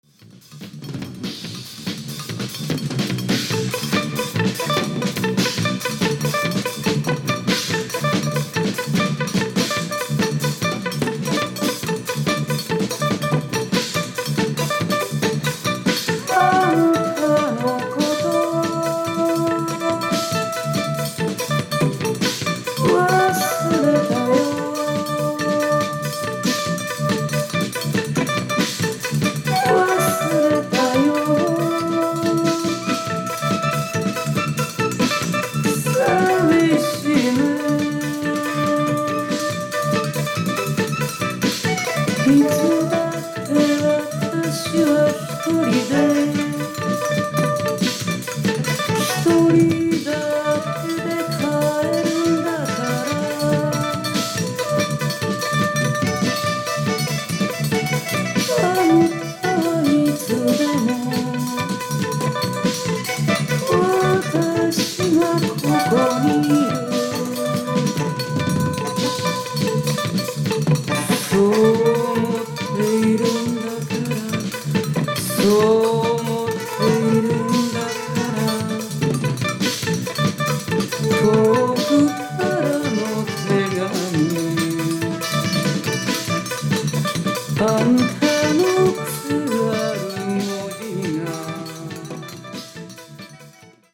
Alto Saxophone, Flute
Electric Bass
Drums, Percussion